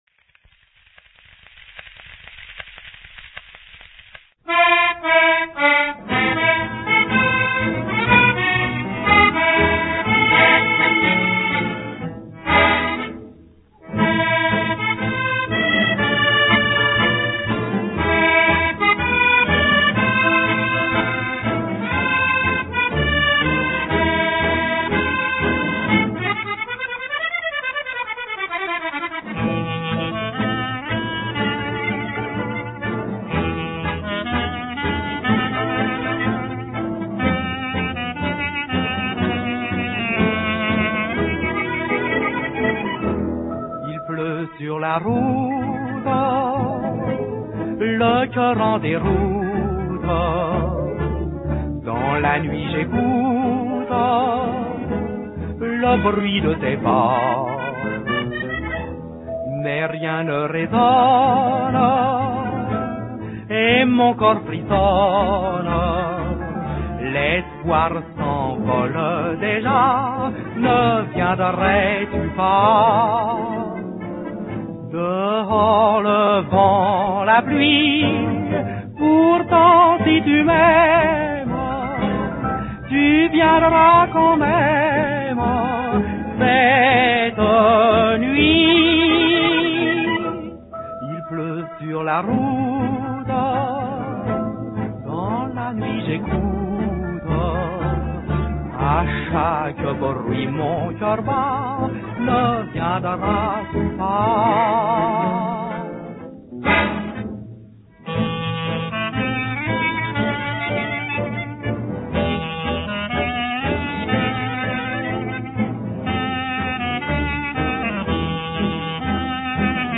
Танцевальная музыка 30-х годов…
Старый патефон
ТАНЦЕВАЛЬНАЯ МУЗЫКА 30-х ГОДОВ.